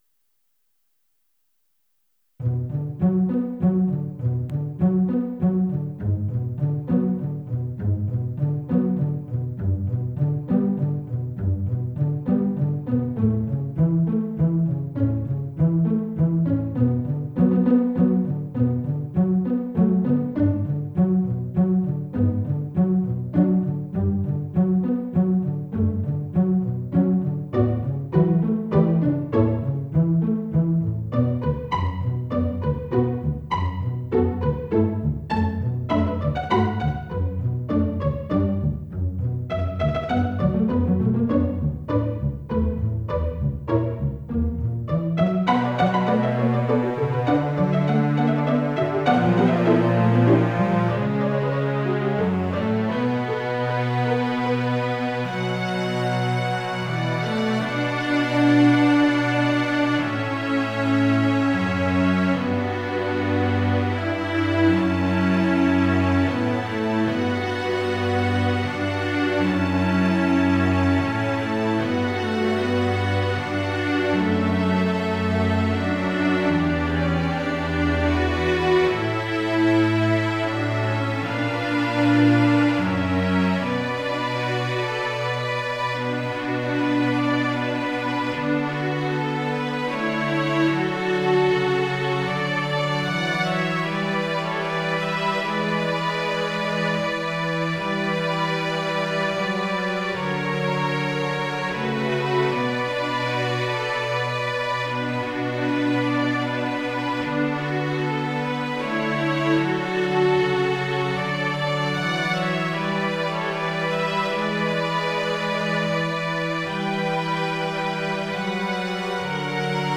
Try the mp3. mp3 download wav download Files: mp3 wav Tags: Quartet, Strings Plays: 1444 Likes: 0